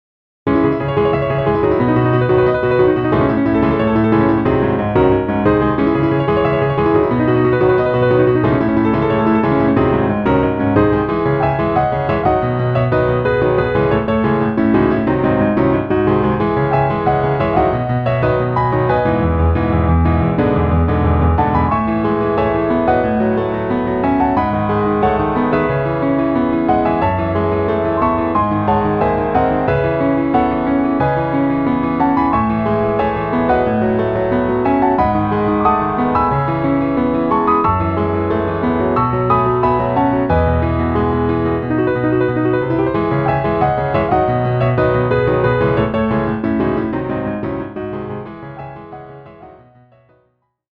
Ivory 3 German D